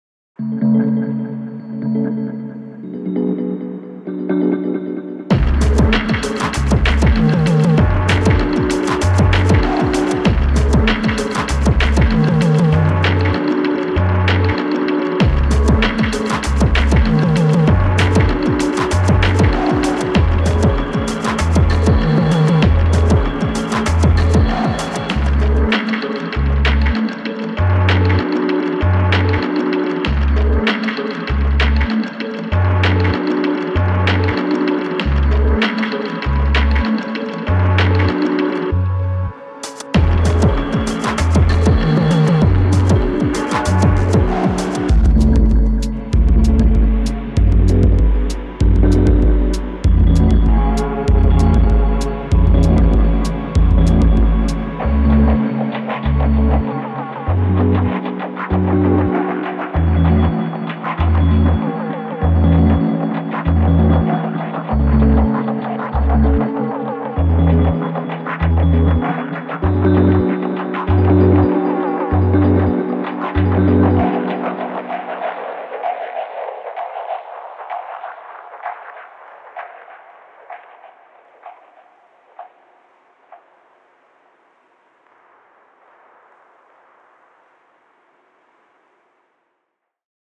Revisited a track from earlier this year, as a deep dive/battle of distortion types and then tried to constrain the effects overall, and might need to keep messing with this way of working on stuff.